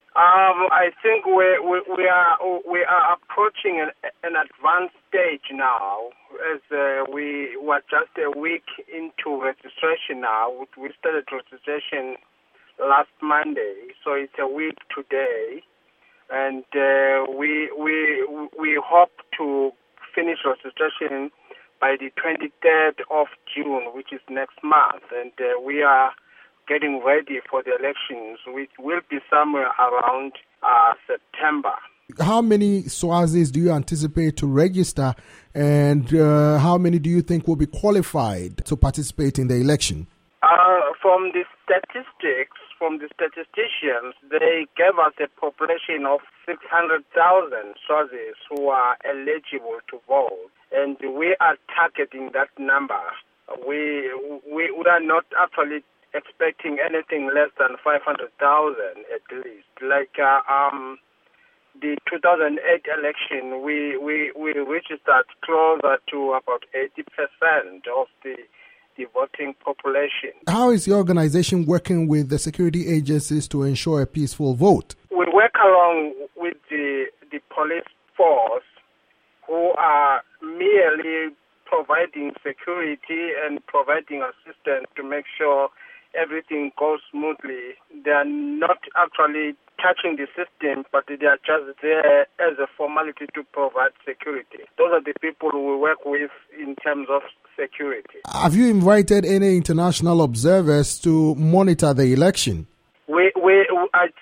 interview with Prince Gija, Swaziland's electoral chief